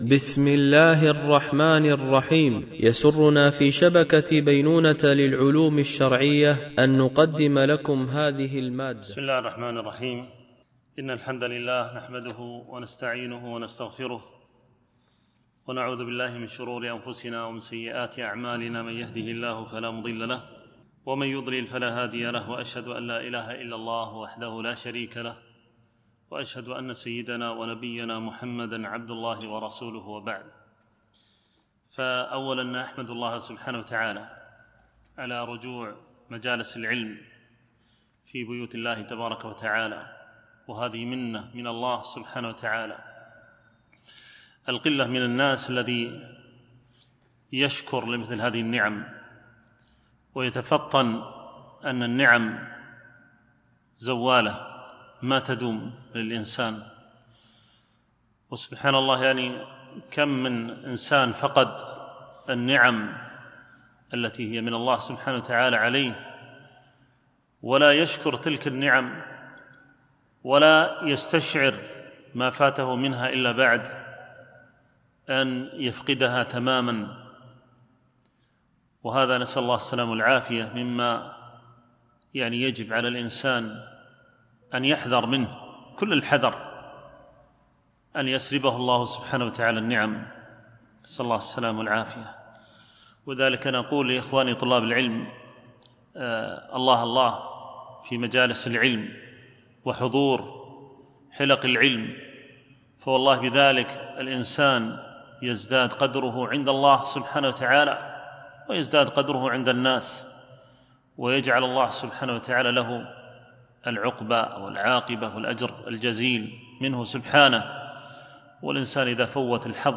شرح المنظومة اللامية في الناسخ والمنسوخ - الدرس 18